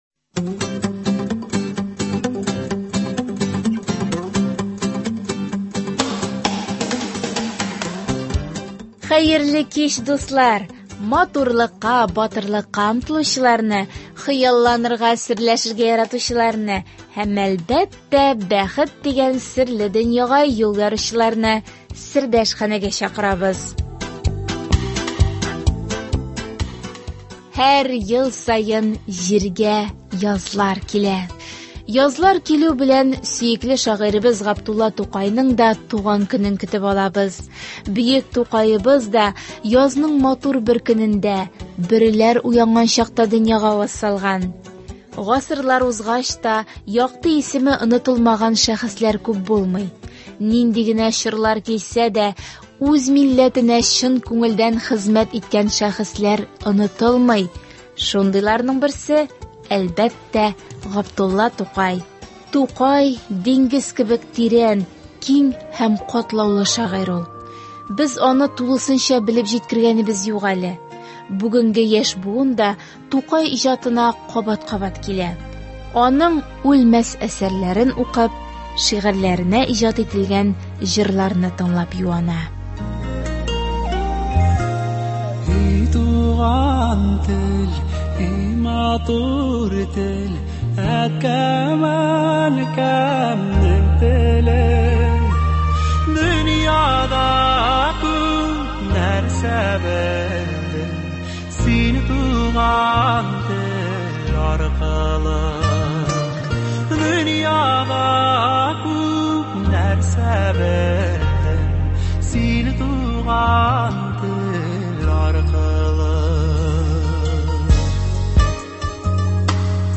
Бүген без дә дусларыбыз укуында Габдулла Тукай шигырьләрен тыңлап, аның безгә җиткерәсе фикерләрен ишетик.